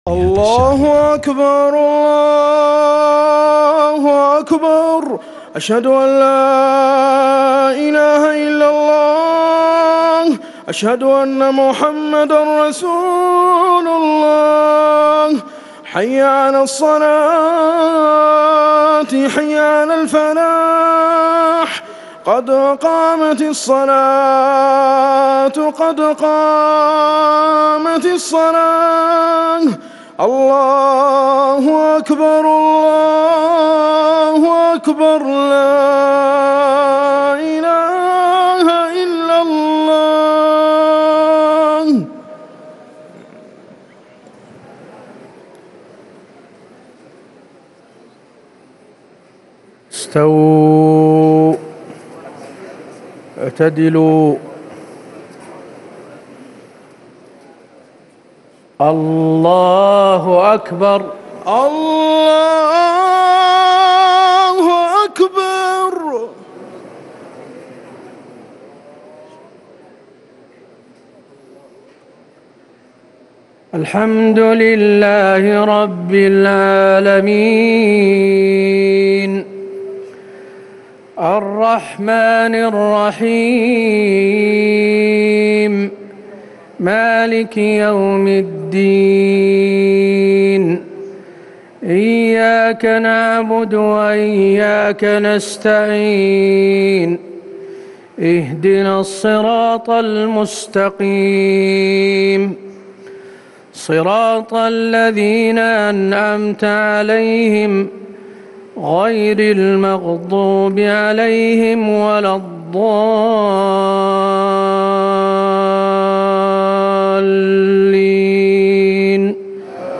صلاة العشاء 2-9-1440هـ من سورة هود | Isha 7-5-2019 prayer from Surah Hud > 1440 🕌 > الفروض - تلاوات الحرمين